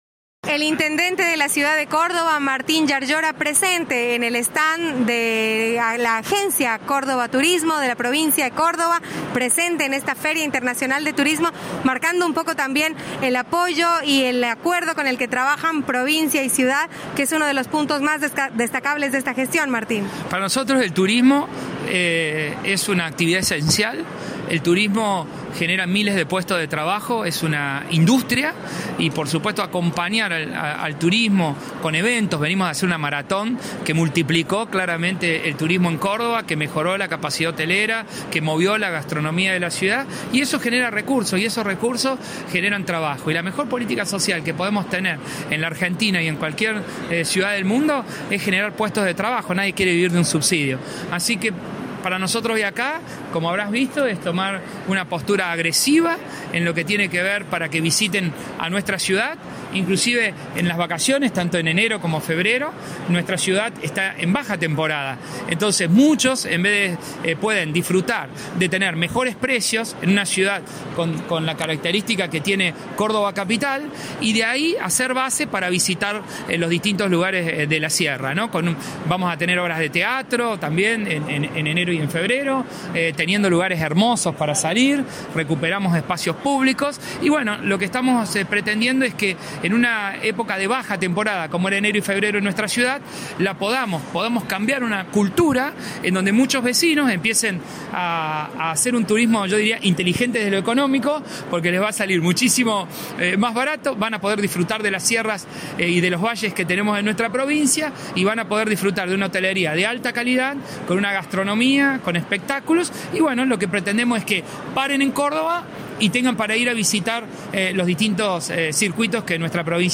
El intendente Martín Llaryora desarrolló la estrategia municipal para que los turistas que elijan vacacionar en los valles de la provincia, también visiten la ciudad. Escuchá la entrevista.